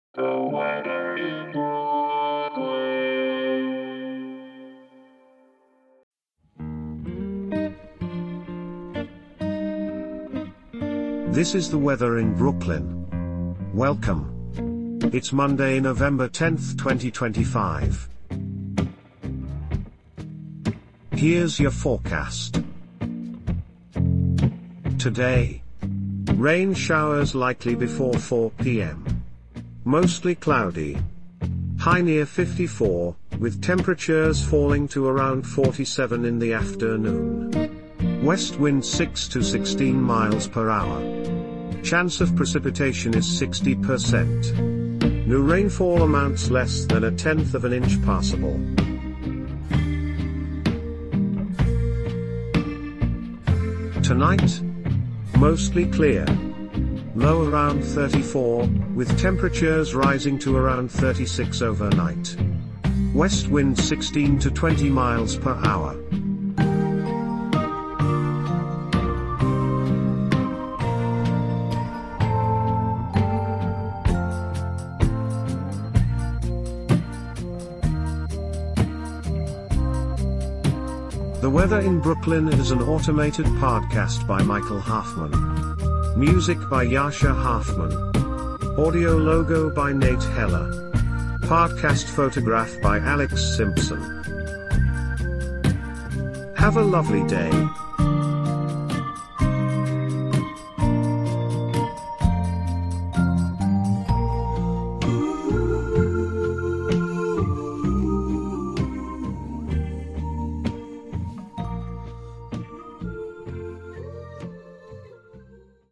is generated automatically.
Weather forecast courtesy of the National Weather Service.